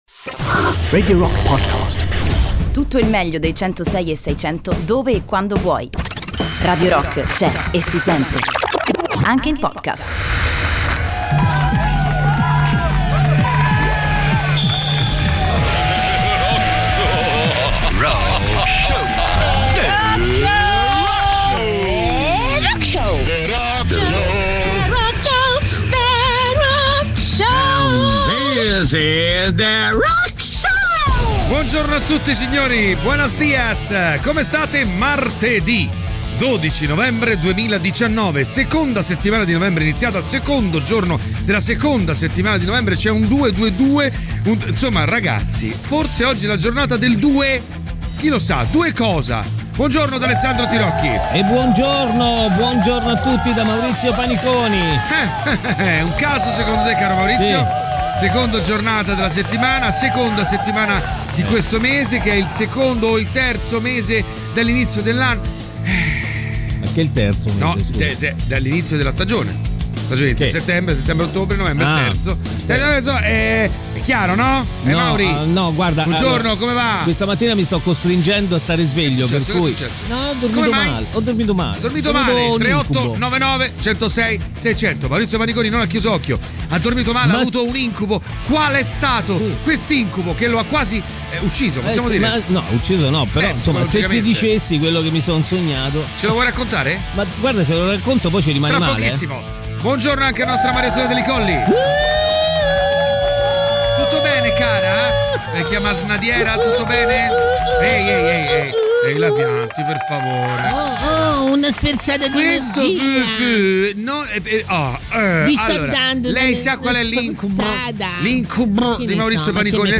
in diretta dalle 06.00 alle 08.00 dal Lunedì al Venerdì sui 106.6 di Radio Rock.